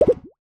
UIClick_Bubbles Splash 01.wav